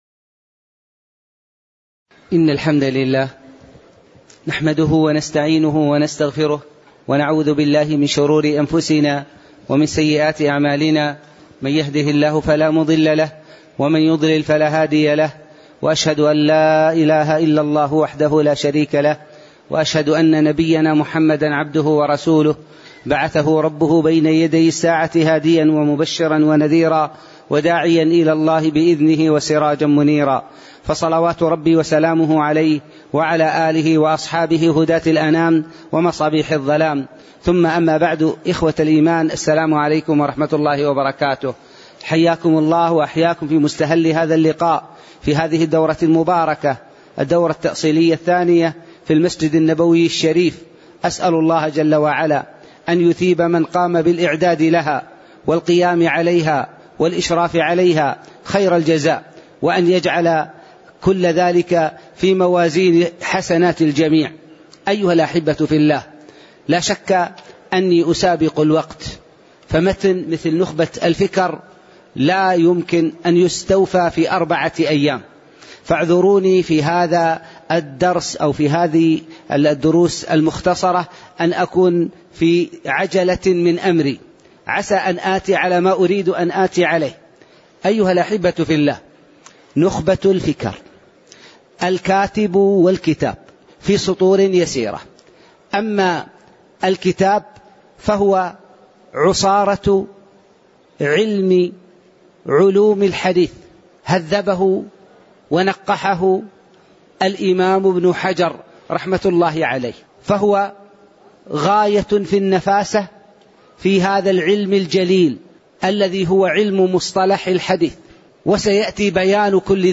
تاريخ النشر ١١ شوال ١٤٣٩ هـ المكان: المسجد النبوي الشيخ